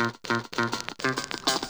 FUNK_GUI.WAV